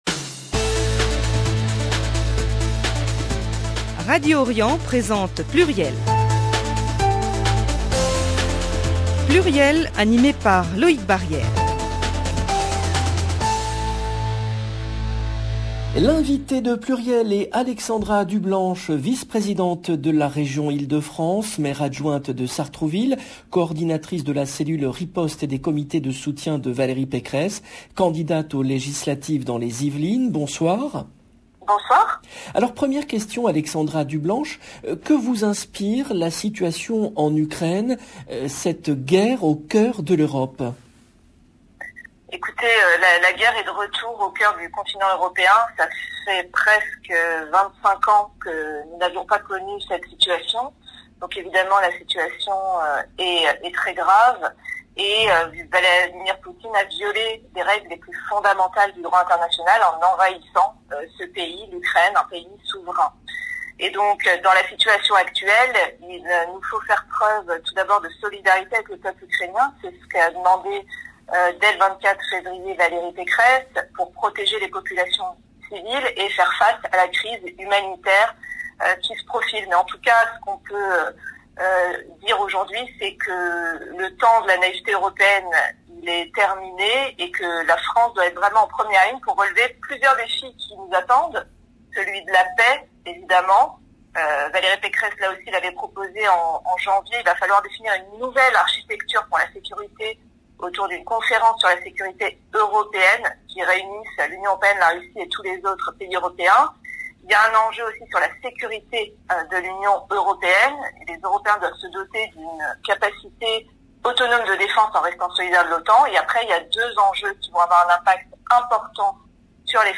Alexandra Dublanche, vice-présidente de la Région Ile-de-France
L’invitée de PLURIEL est Alexandra Dublanche, vice-présidente de la Région Ile-de-France, maire-adjointe de Sartrouville, coordinatrice de la cellule riposte et des comités de soutien de Valérie Pécresse, candidate aux législatives dans les Yvelines.